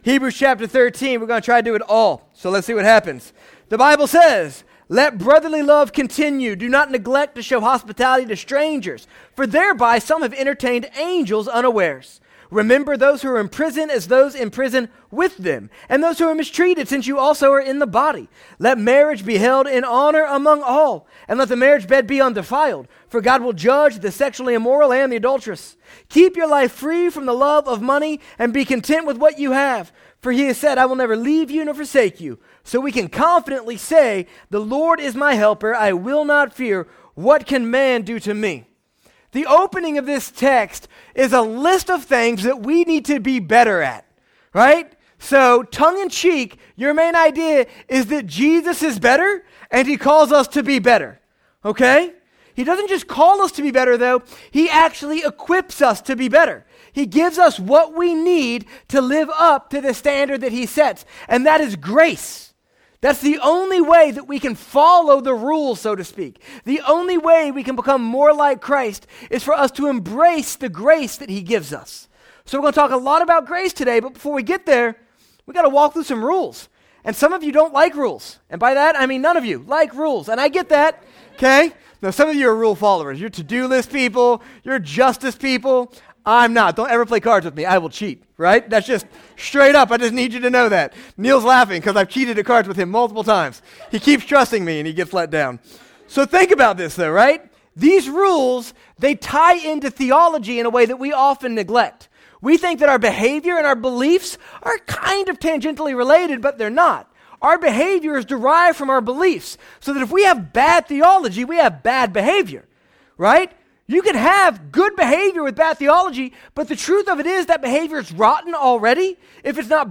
Sermons | South Shore Church